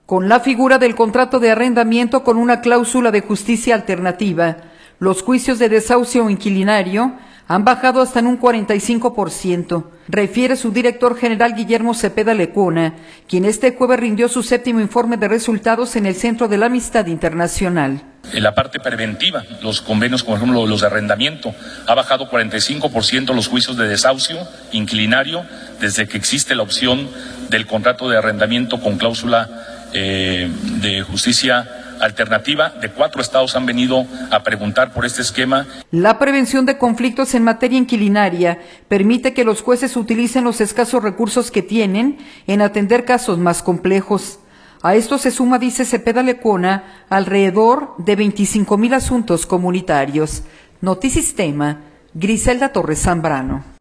audio Con la figura del contrato de arrendamiento con una clausula de Justicia Alternativa, los juicios de desahucio inquilinario han bajado hasta en un 45 por ciento, refiere su director general, Guillermo Zepeda Lecuona, quien este jueves rindió su séptimo informe de resultados en el Centro de la Amistad Internacional.